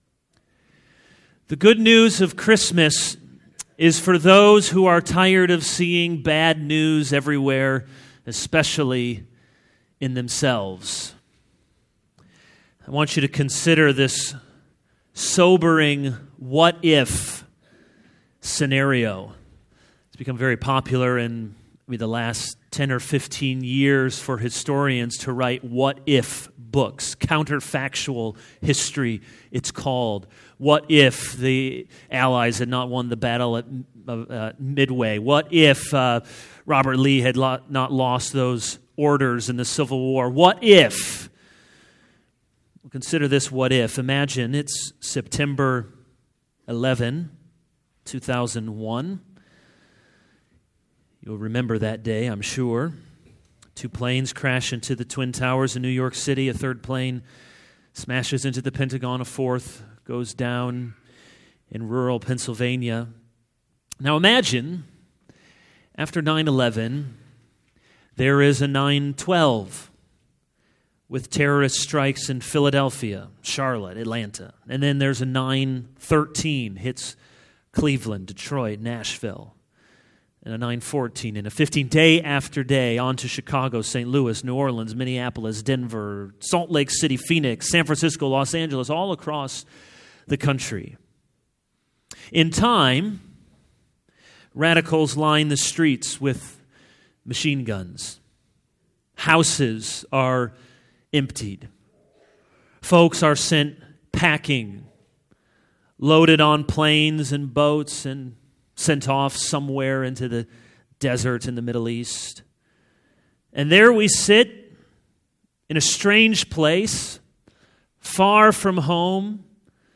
All Sermons Comfort, Comfort My People 0:00 / Download Copied! Share Isaiah 40:1-40:5 | Dec 27, 2015 Comfort, Comfort My People The good news of Christmas is for those who are tired of seeing bad news everywhere, especially in themselves.